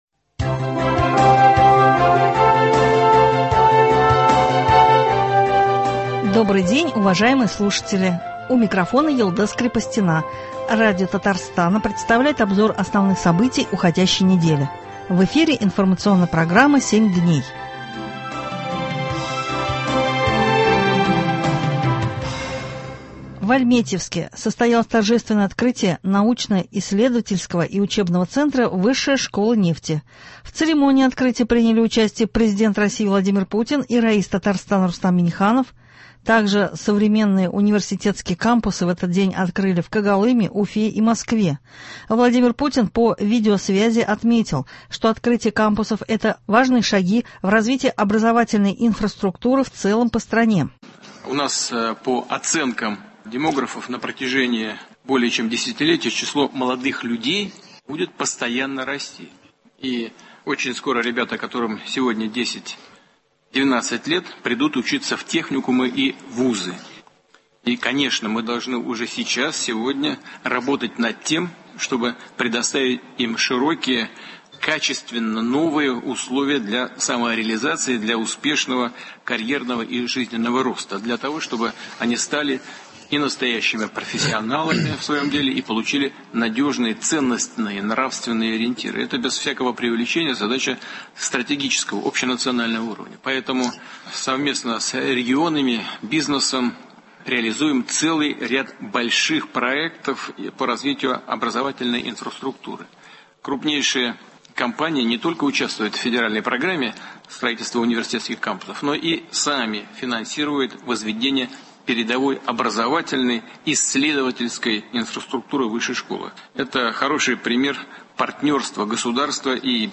Обзор событий недели.